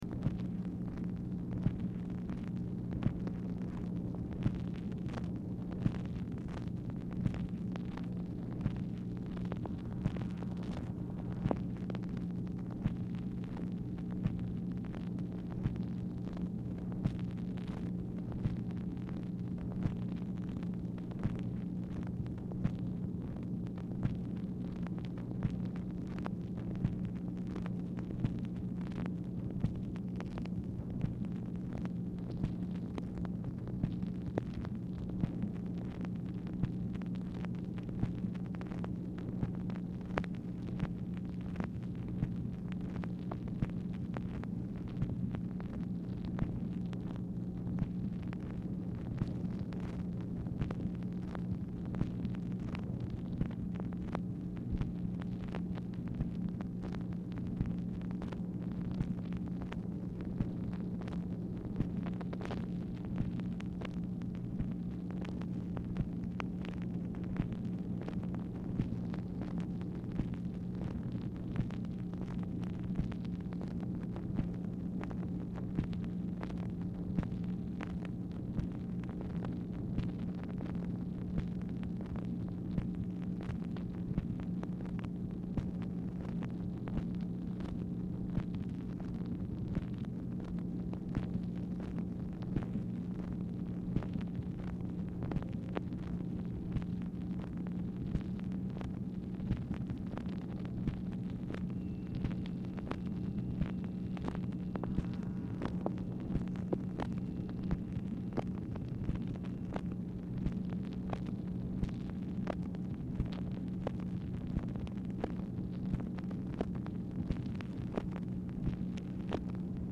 Telephone conversation # 7668, sound recording, MACHINE NOISE, 5/14/1965, time unknown | Discover LBJ
Format Dictation belt
Specific Item Type Telephone conversation